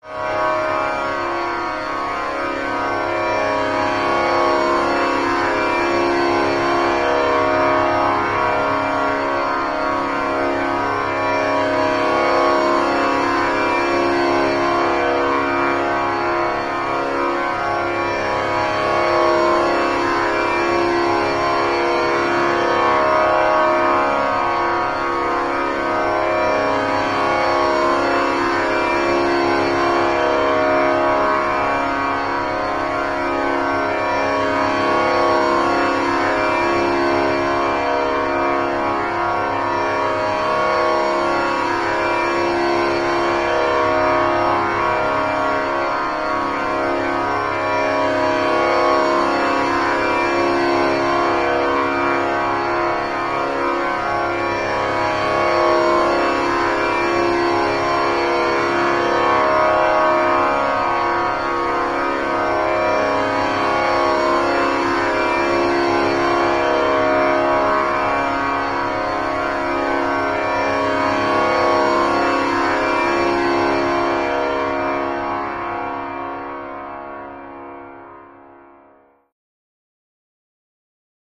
Mystic Instrument Ambience And Tonal Eastern Instrument Mystic, Eastern, Instrument